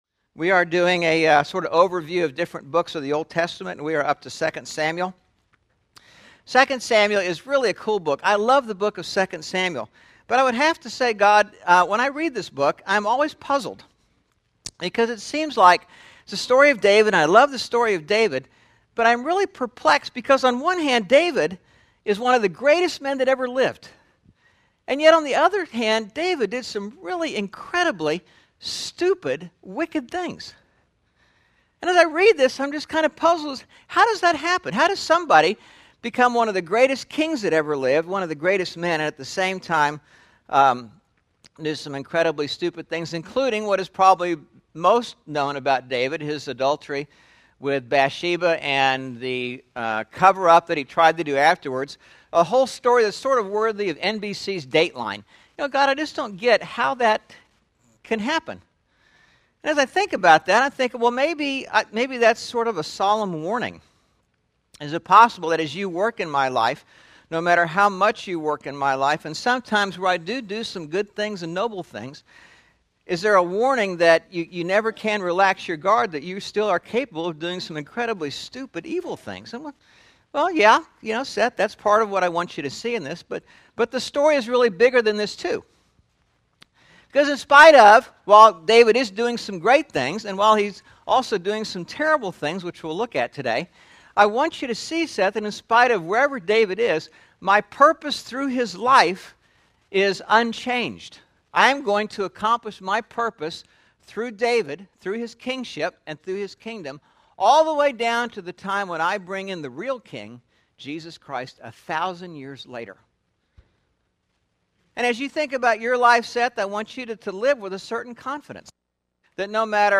8/14/11 Sermon (The Book of 2 Samuel) – Churches in Irvine, CA – Pacific Church of Irvine